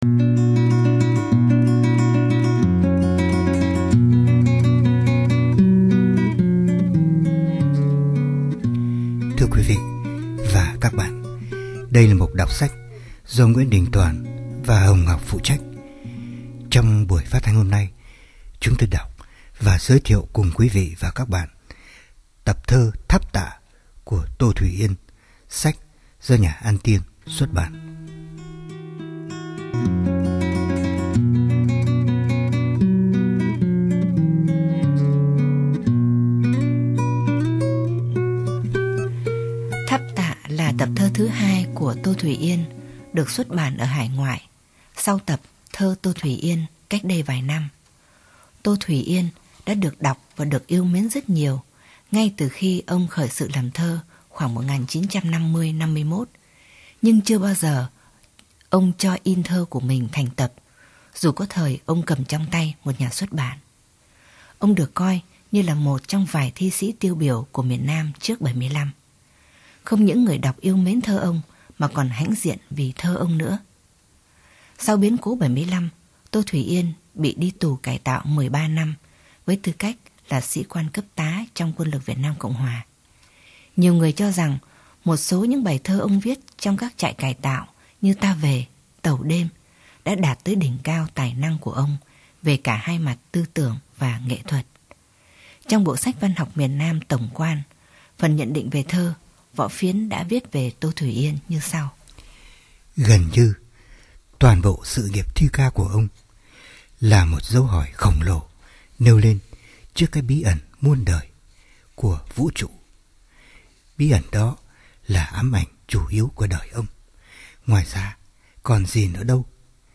Giọng đọc